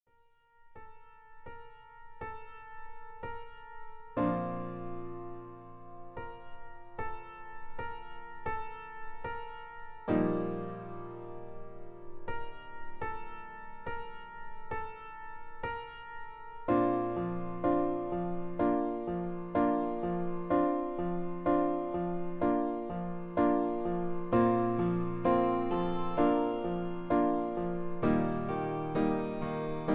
backing tracks
easy litstening, top 40, love songs, ballads